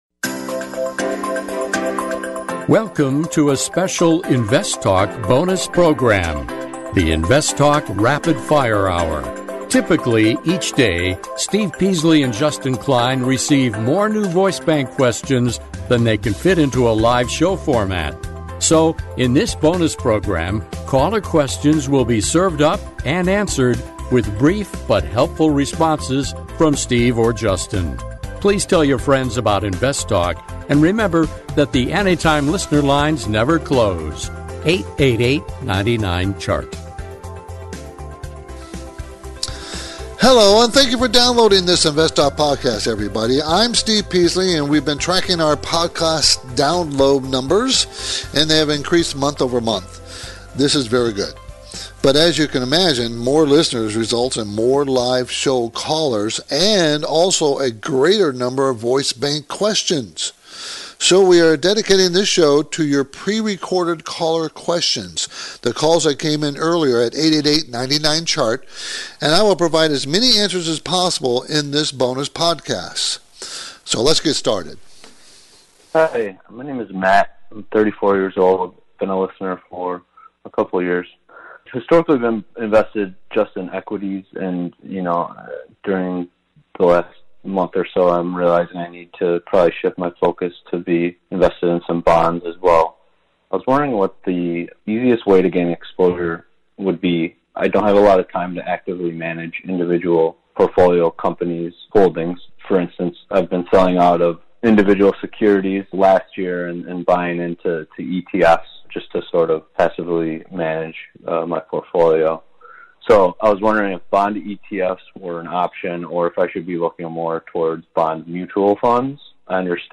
This special InvestTalk “Rapid Fire Hour” features brisk-paced answers to 29 listener questions.